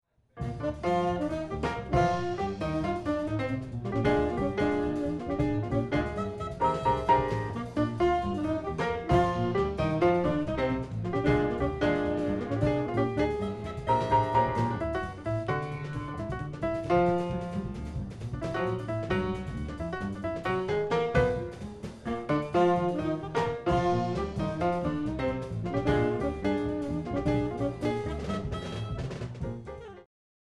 Saxophone
Bass
Drums
Piano